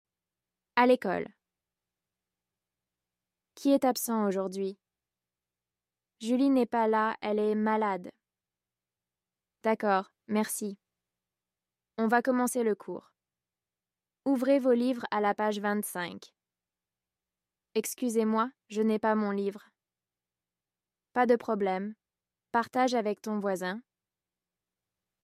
Dialogue FLE – À l’école (niveau A2)